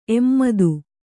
♪ emmadu